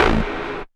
tekTTE63008acid-A.wav